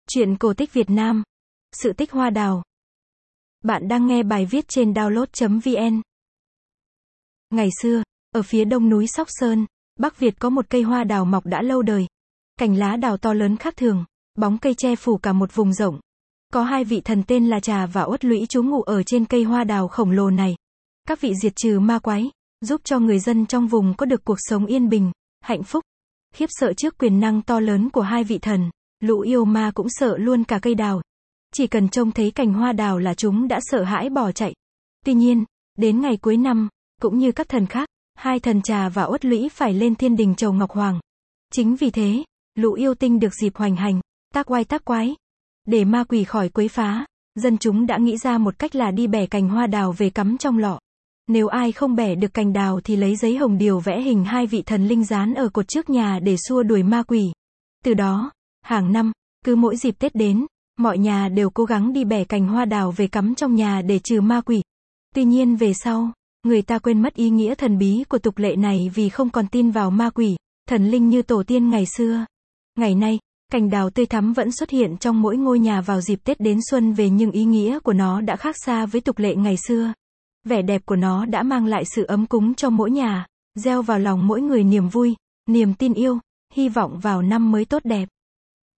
Sách nói | Sự tích hoa đào